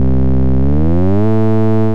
• 2. ピッチ・グライド[05xx]・・・
最初はC-4が鳴っていて、ライン04からA-4に向かって20（16進法）の割合でピッチが上昇します。A-4まで上がりきるとピッチの上昇は止まります。